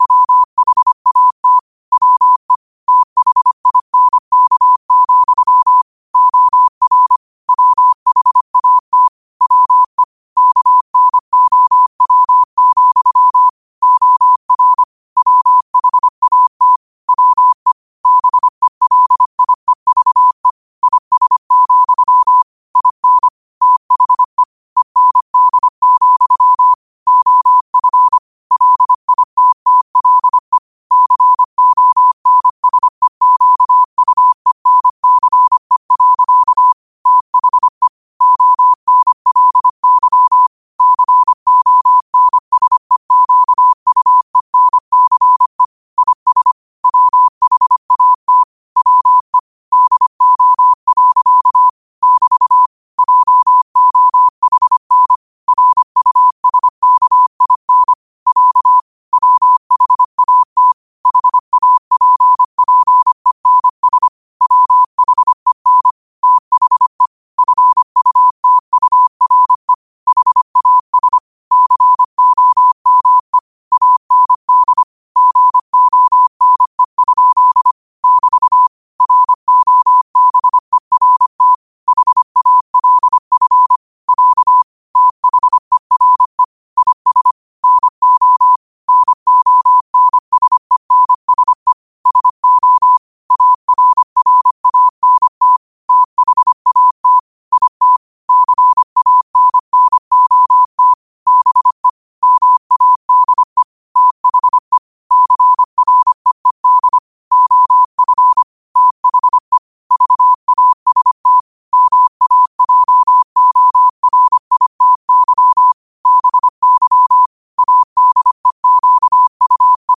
New quotes every day in morse code at 25 Words per minute.